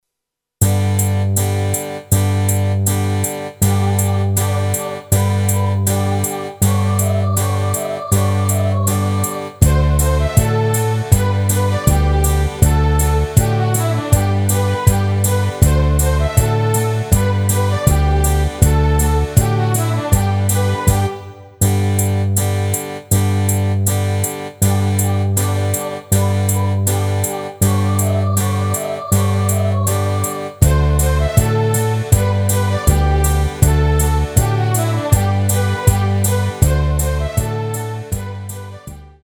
Rubrika: Vánoční písně, koledy
HUDEBNÍ PODKLADY V AUDIO A VIDEO SOUBORECH